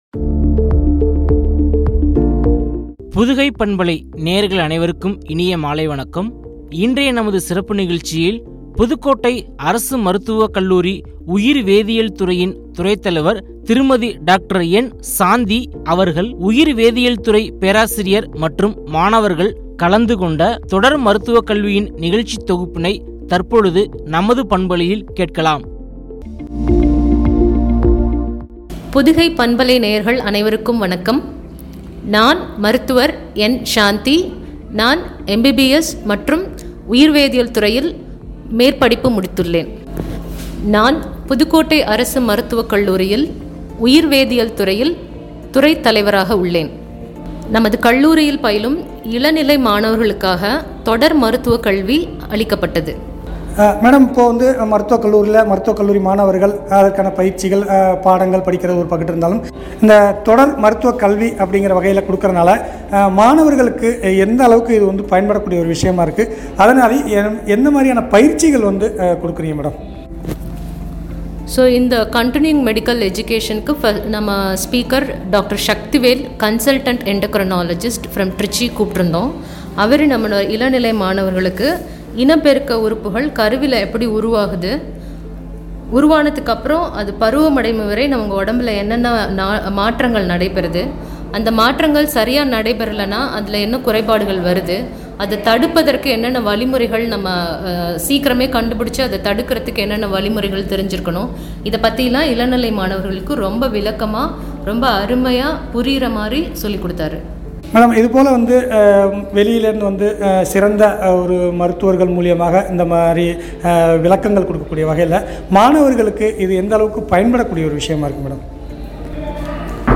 ” குறித்த உரையாடல்.